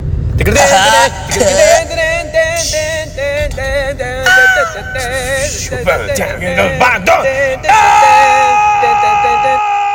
トムの足に重い物が乗った時のトムの叫び声 / トムとジェリー